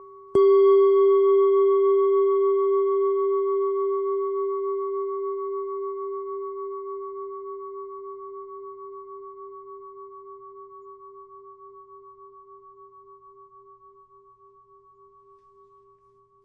铃铛碗Gish
描述：一个中等（黄铜？）响铃碗的单环，带有软槌。大约G天然。
Tag: 钟声